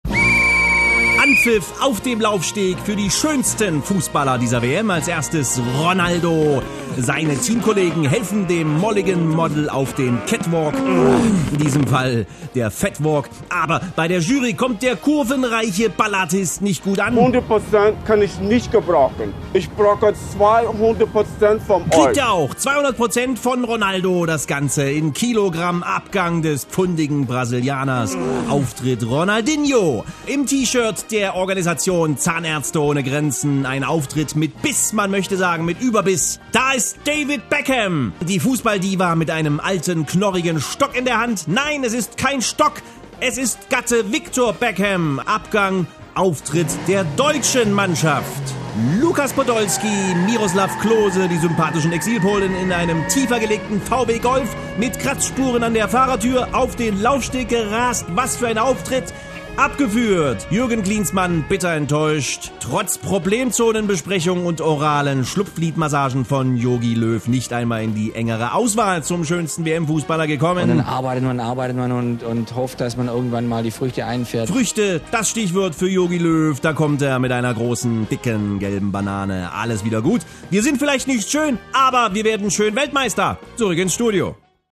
Er kann nicht anders: Er muss alles was morgens in Deiner Familie passiert kommentrieren: Als Fußballspiel!